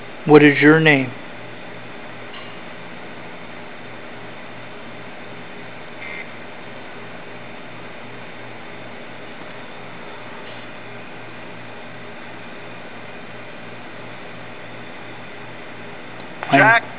Ghost Box Clip 1 (AM Band) :25 into the session Clip 2 (AM Band) 1:02 into the session Clip 3 (AM Band) 3:30 into the session Clip 4 (AM Band) 5:30 into the sessio Clip 5 (AM Band) 6:58 into the session